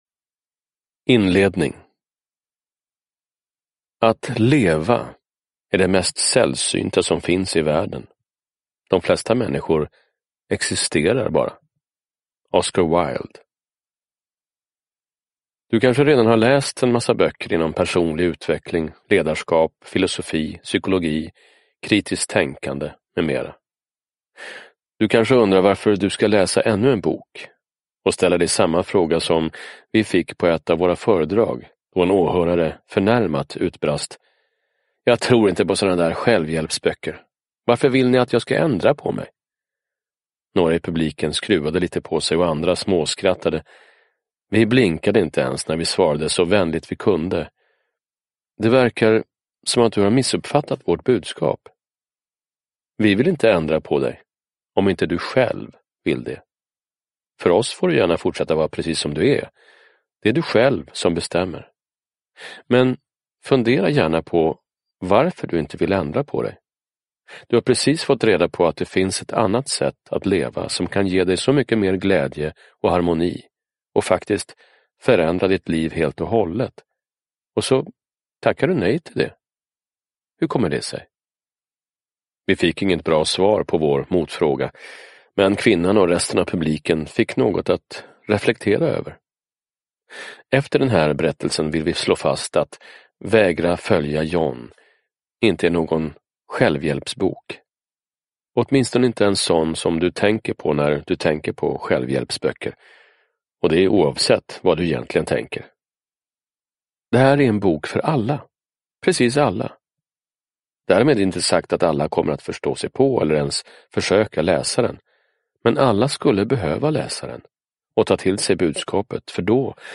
Vägra följa John : hemligheten bakom ett lyckligt liv / Ljudbok
Uppläsare: Anders Ekborg
Ljudbok